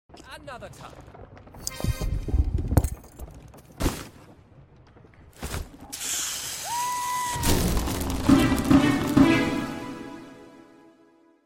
Sound effects of Geometry Dash sound effects free download
Sound effects of Geometry Dash 2.2 - "The Tower" mode